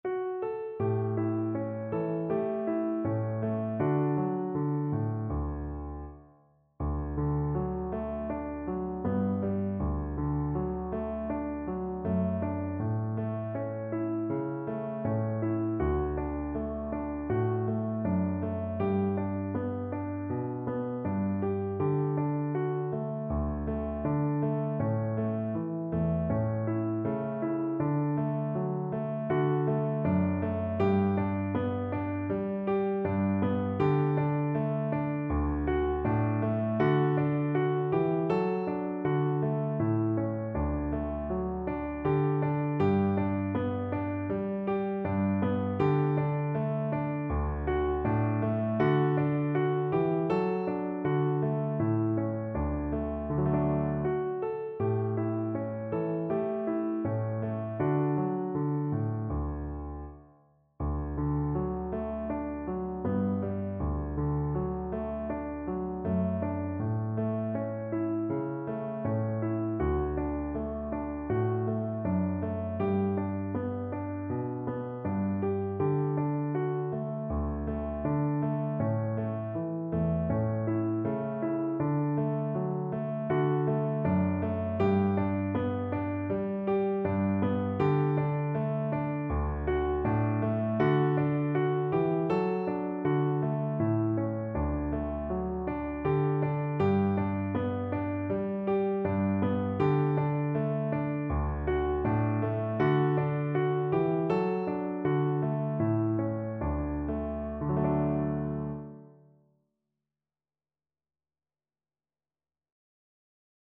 Cello version
4/4 (View more 4/4 Music)
Andante
Classical (View more Classical Cello Music)